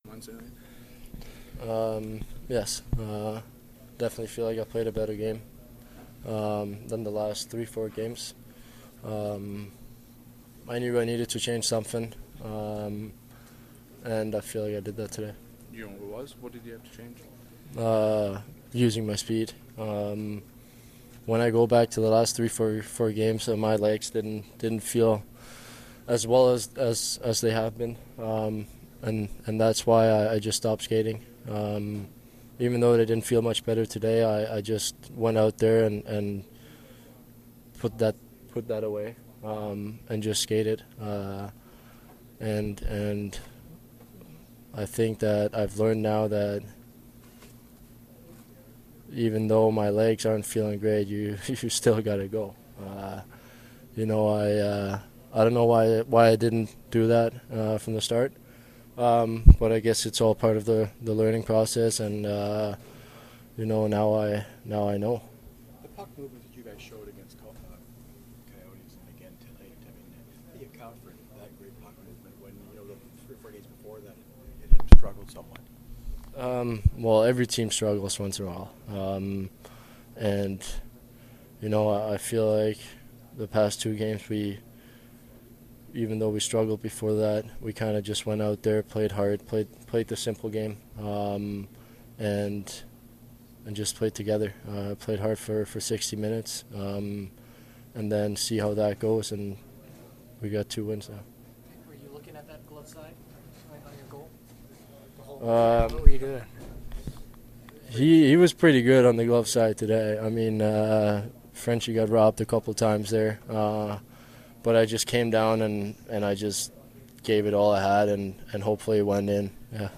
Post-game from the Jets and Blues dressing rooms as well as from Coach Maurice and Coach Hitchcock.